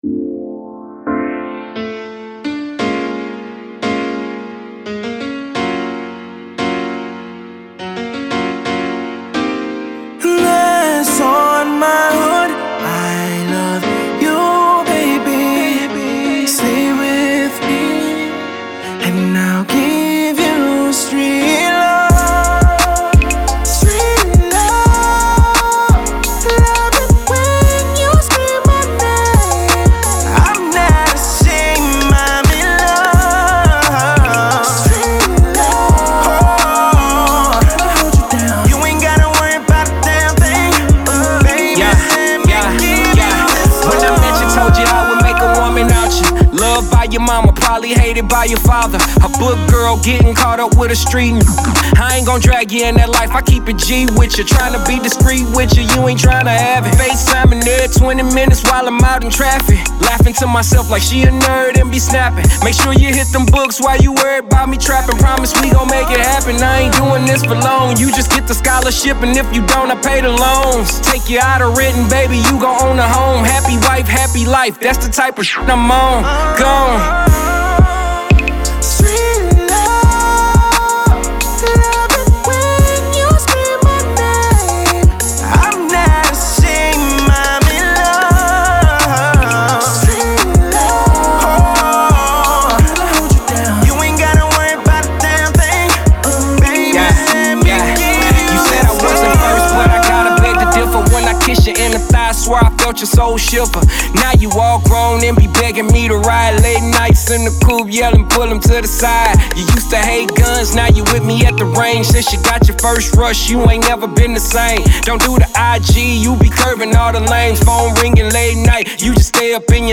samples an old school track for new single